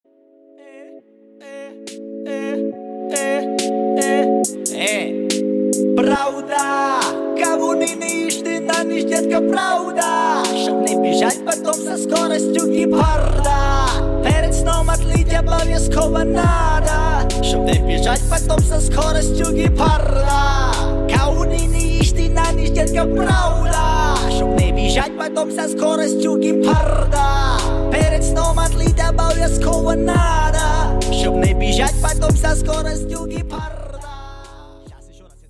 • Качество: 146, Stereo
ритмичные
веселые